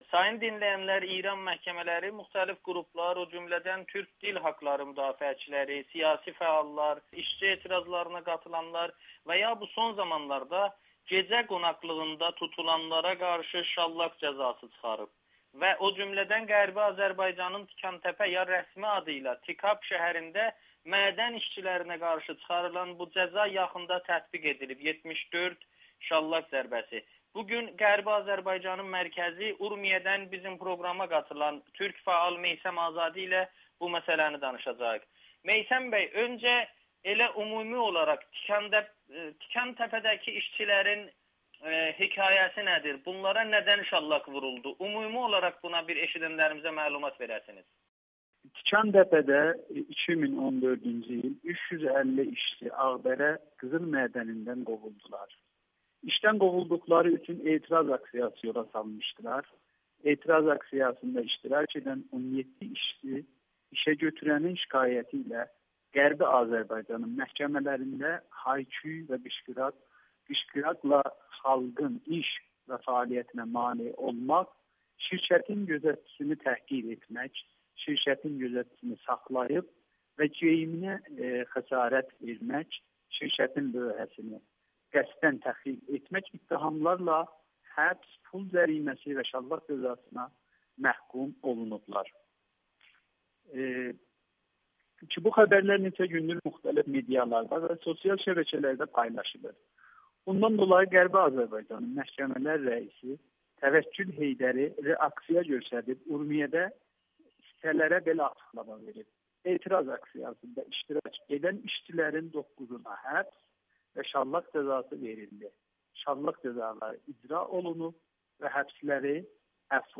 Tikantəpəli işçilərin problemi sadəcə şallaq hökmü deyil [Audio-Müsahibə]